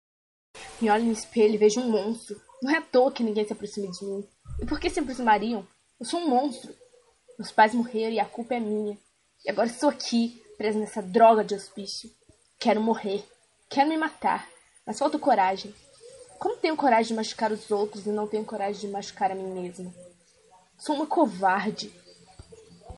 Sermão Teste 3
teste_pregacao.mp3